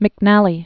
(mĭk-nălē), Terrence Born 1939.